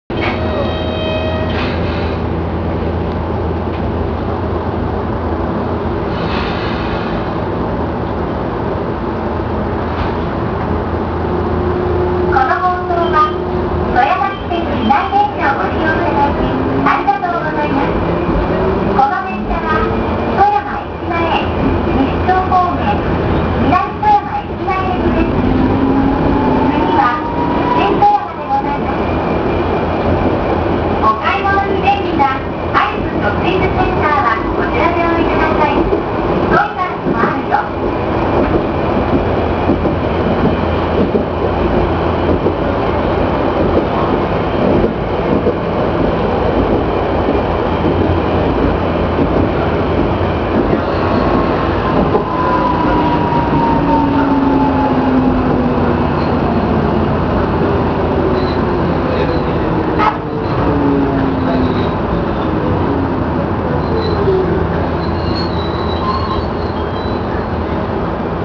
〜車両の音〜
・8000形走行音
【市内軌道線】大学前→新富山（1分8秒：373KB）
富山地鉄初にして唯一のGTO-VVVF車であり、東洋の初期型IGBTを採用。